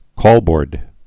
(kôlbôrd)